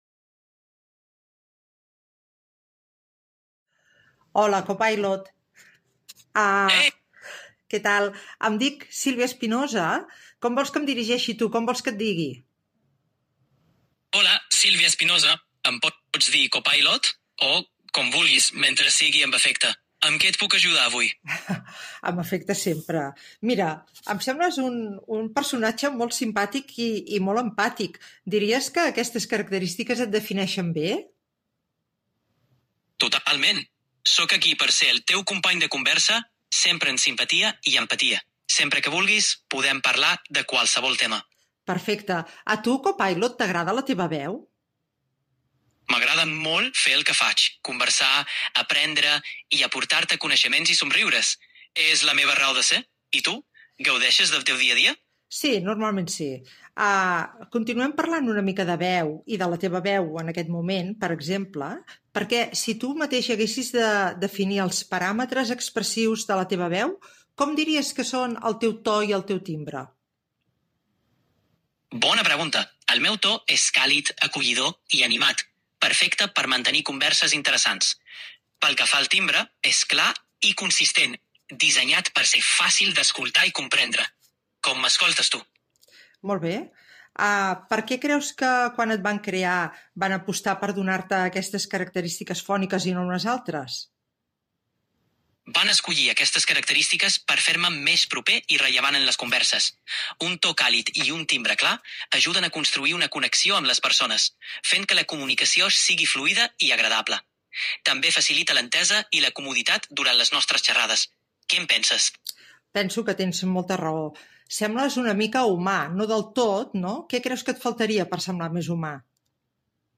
A continuació podeu escoltar un pòdcast que he elaborat amb un assistent de veu d'intel·ligència artificial.